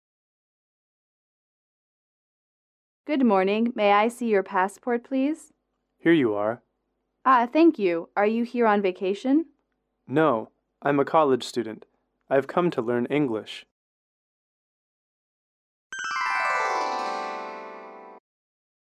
英语主题情景短对话17-1：身份安检(MP3)